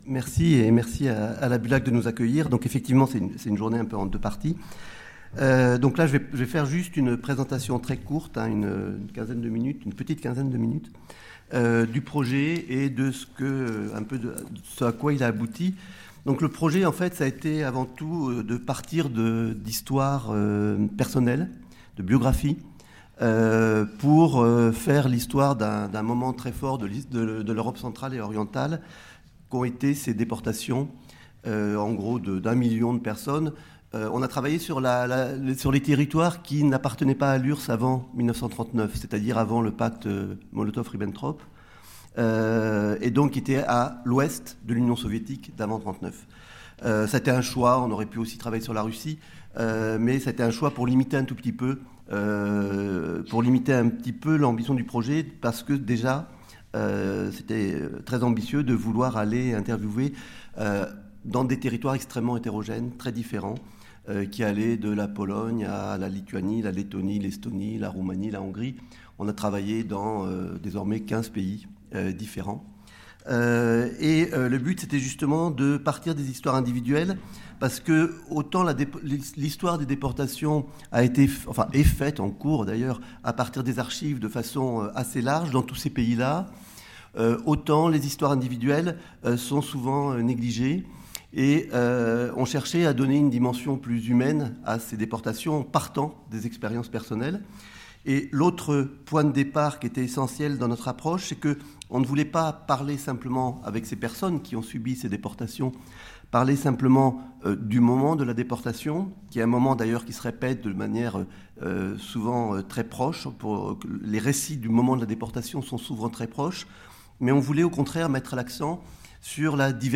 Cette journée d'étude est coordonnée par les missions Action culturelle et bibliothèque numérique de la BULAC et le CERCEC, en partenariat avec RFI et les éditions Autrement, avec le concours de la mission Communication externe de la BULAC.